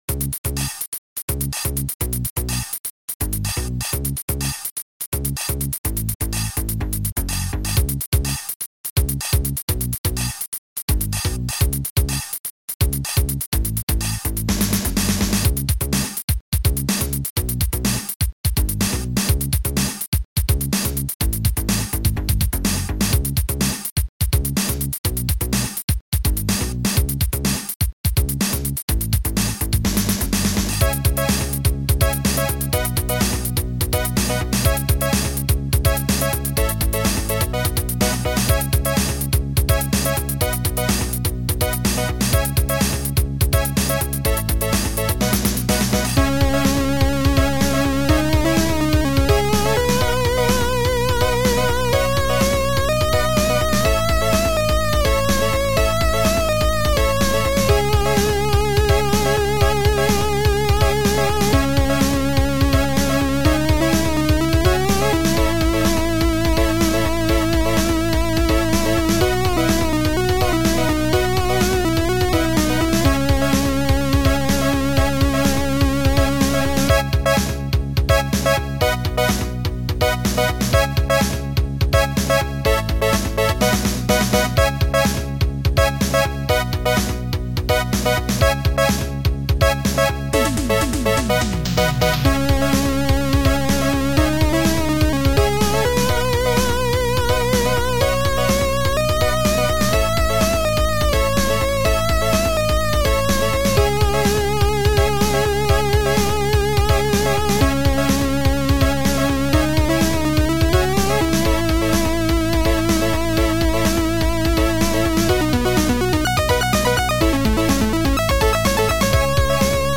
Sound Format: Future Composer 1.4